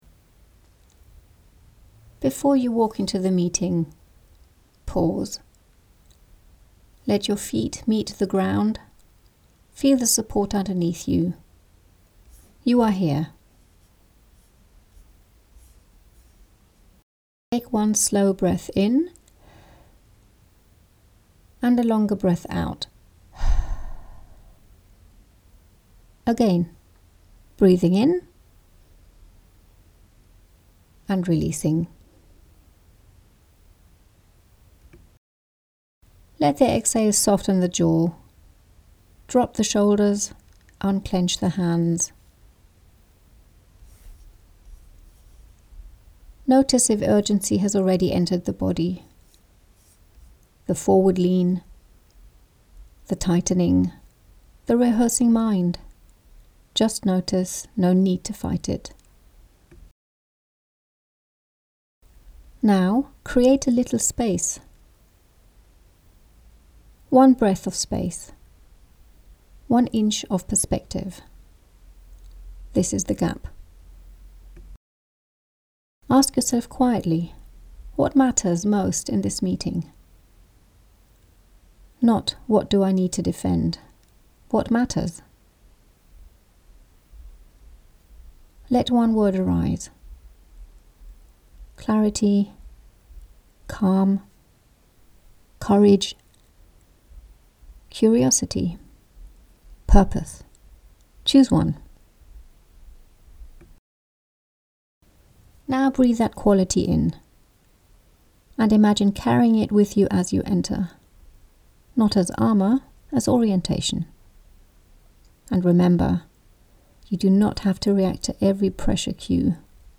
Geführte Impulse